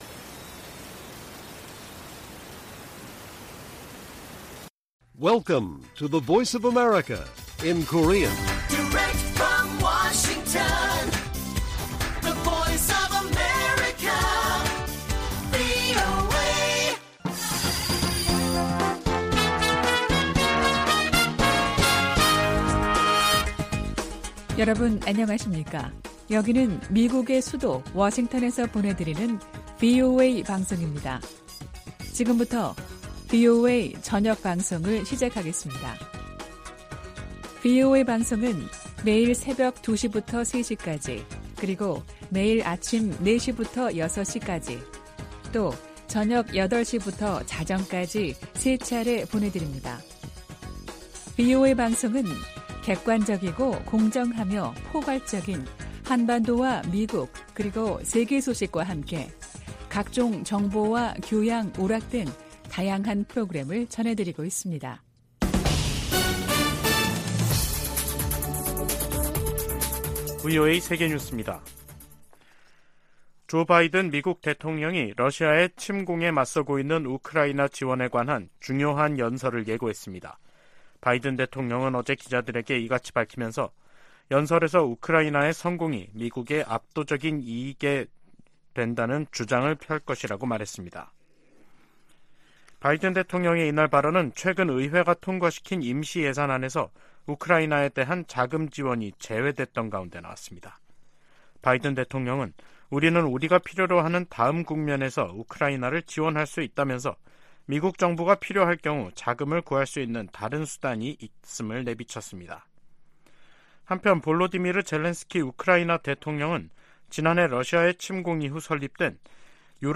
VOA 한국어 간판 뉴스 프로그램 '뉴스 투데이', 2023년 10월 5일 1부 방송입니다. 로이드 오스틴 미 국방장관과 기하라 미노루 일본 방위상이 북한의 도발과 중국의 강압, 러시아의 전쟁을 미-일 공통 도전으로 규정했습니다. 미국 국무부가 제재 대상 북한 유조선이 중국 영해에 출몰하는 데 대해, 사실이라면 우려한다는 입장을 밝혔습니다. 한국 헌법재판소가 대북전단금지법에 위헌 결정을 내리면서 민간단체들이 살포 재개 움직임을 보이고 있습니다.